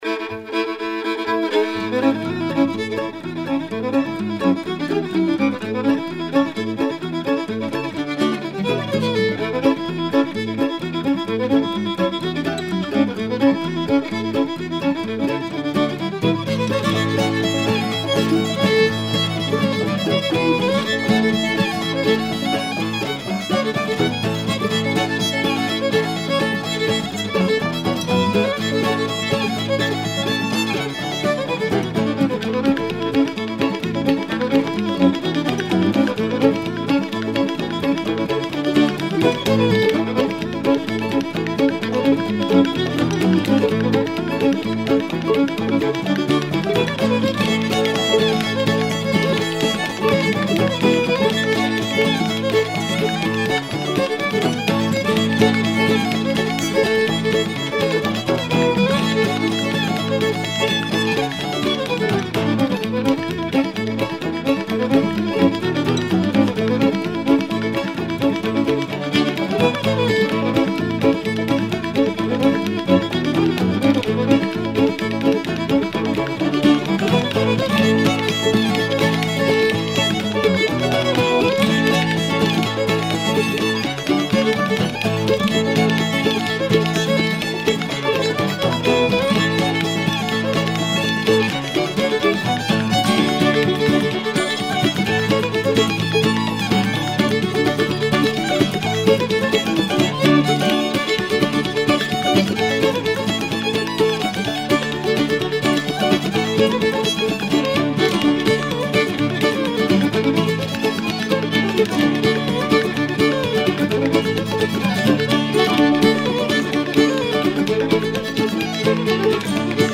représentatif des violoneux de Mouvlle-Angleterre
d'inspiration écossaise influencé par la tradition du jeu du Canada franbçais
danse : reel